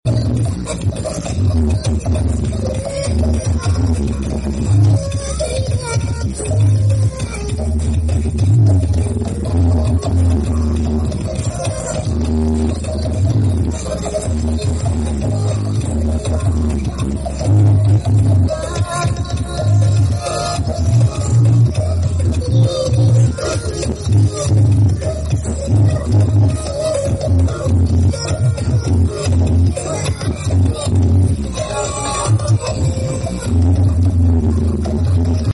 battle sengit ngaban tanggulangin Sidoarjo sound effects free download